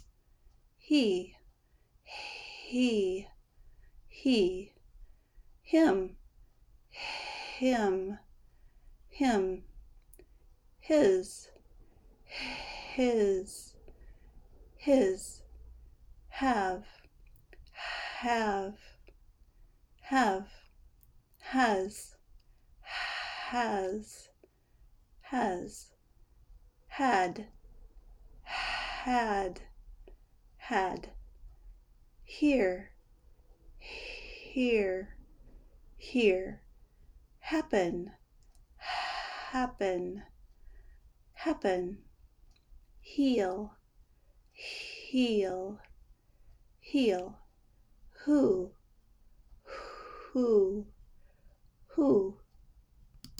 The sound of h is a breath of air. It is always unvoiced, so you will never use your vocal cords when you pronounce it.
Practice these words with H